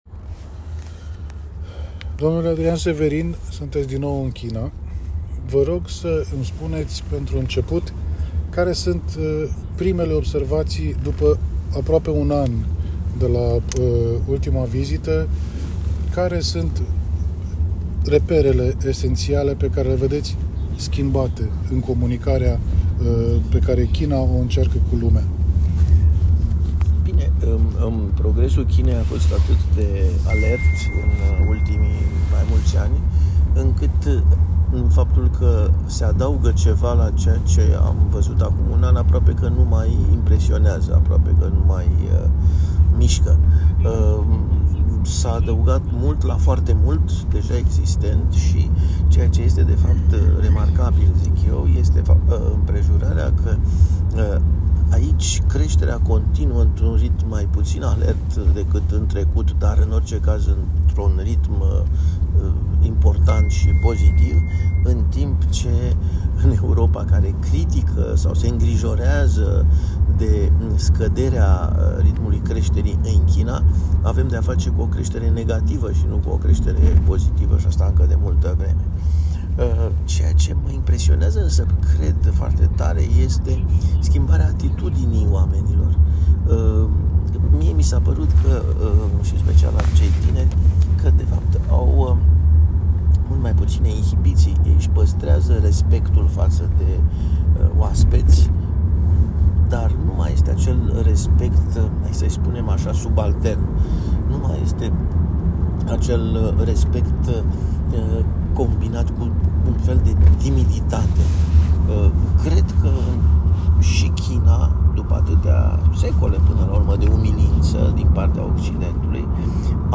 Asta nu înseamnă să ne certăm cu America, nu înseamnă să ne retragem din alianțele actuale, declară Prof.Dr. Adrian Severin (foto), fost ministru român de Externe, fost vice-Prim ministru, fost președinte al Adunării Parlamentare OSCE și membru al Parlamentului European, în cadrul unui dialog purtat la Beijing.